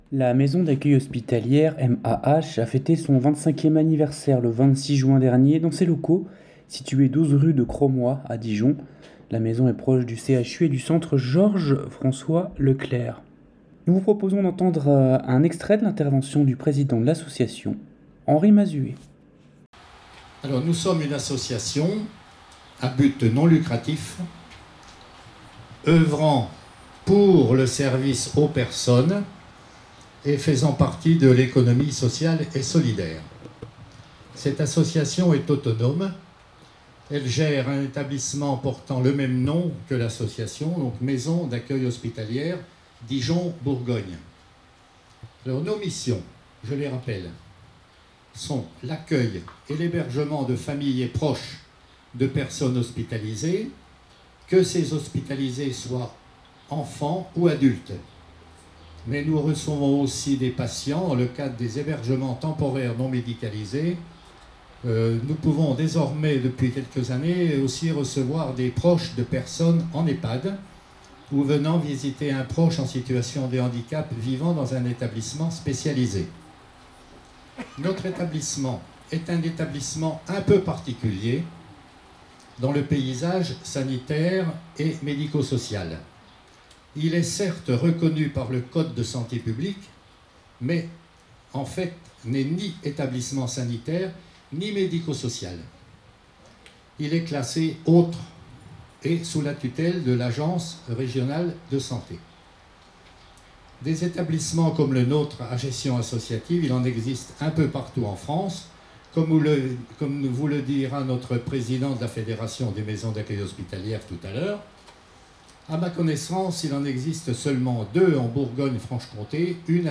La Santé à venir La Maison d’Accueil Hospitalière (MAH) a fêté son 25 ième anniversaire le 26juin dans ses locaux 12 rue de Cromois à Dijon.